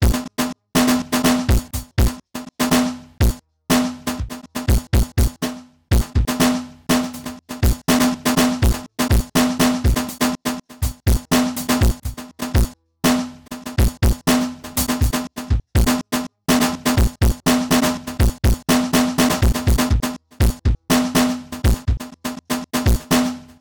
DrumLoop09.wav